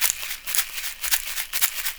African shaker-3 120bpm .wav